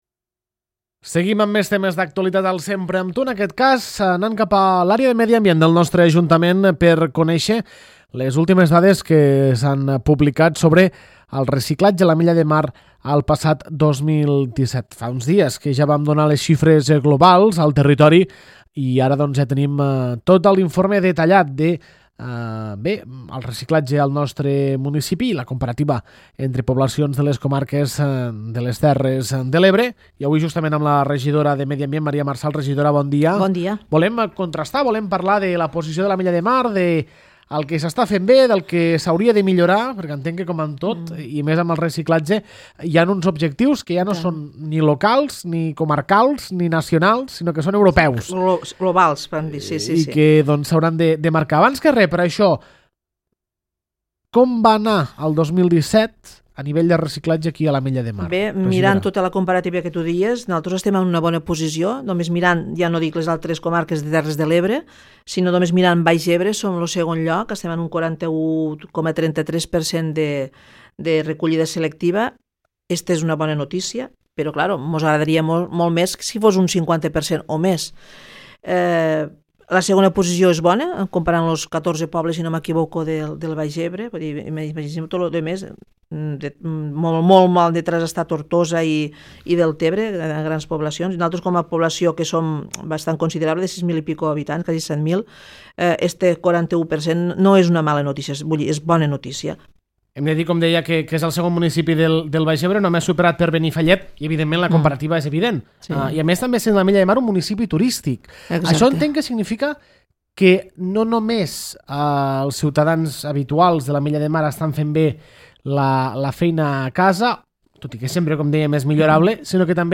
Maria Marsal, regidora de Medi Ambient